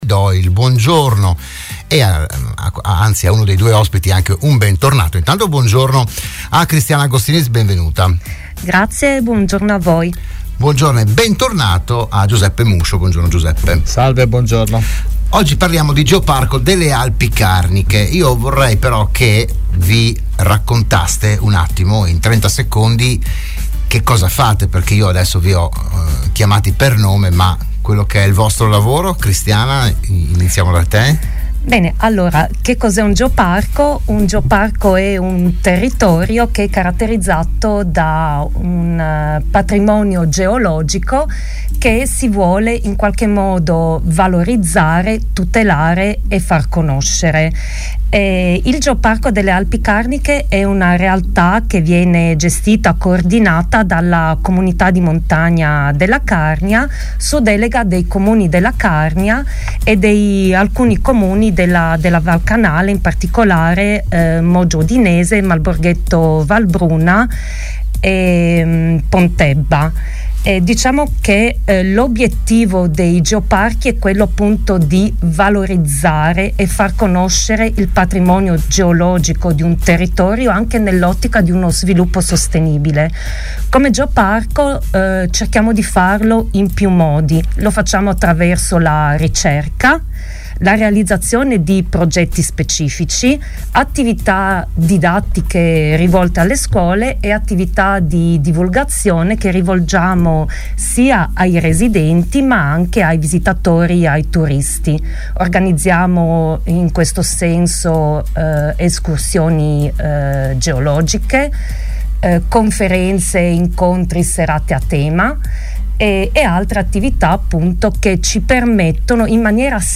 Se n'è parlato a Radio Studio Nord